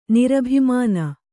♪ nirabhimāna